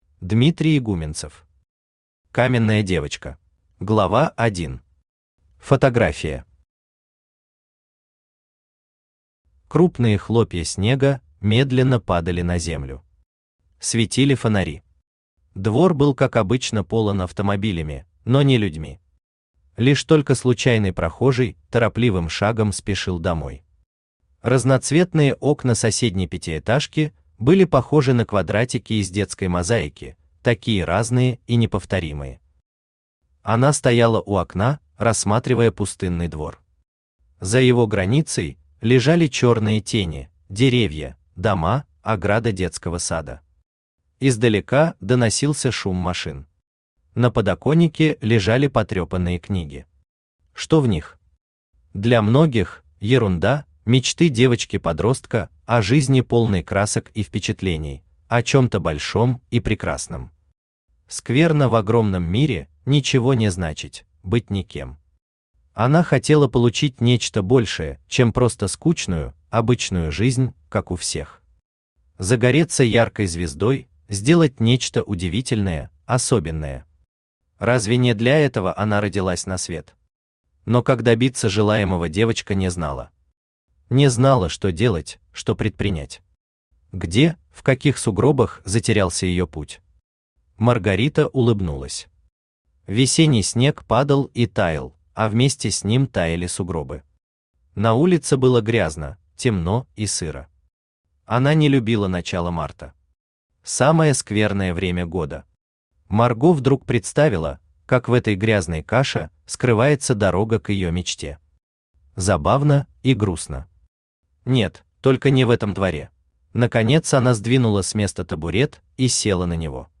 Читает: Авточтец ЛитРес
Аудиокнига «Каменная девочка».